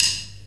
D2 SDRIM04-L.wav